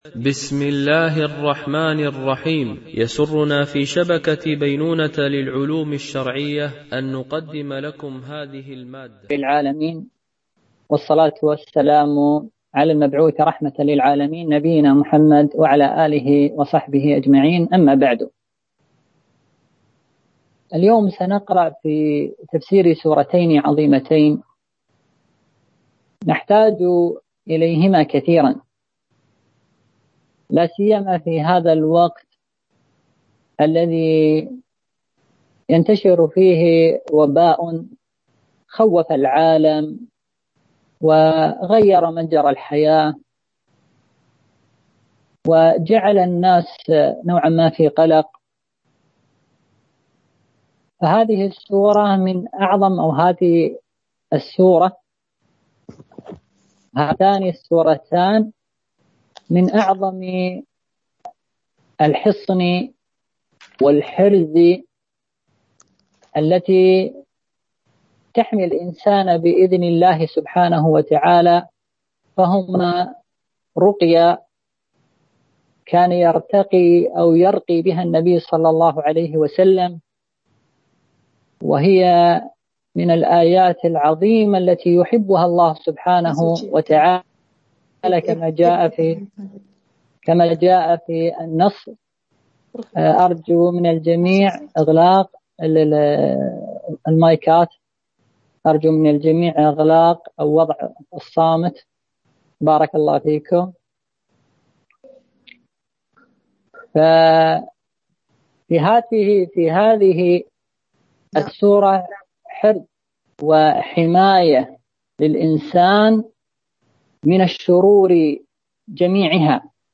سلسلة محاضرات في تفسير القرآن الكريم - المحاضرة 5 ( سورتي الفلق والناس)